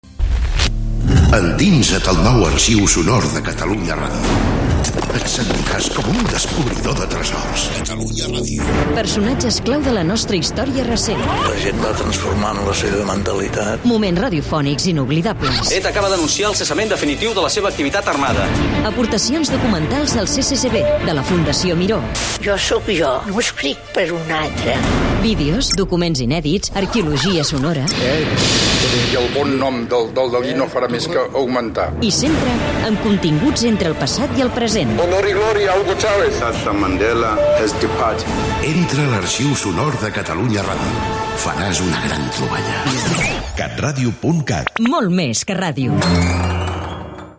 Promoció de l'Arxiu Sonor de Catalunya Ràdio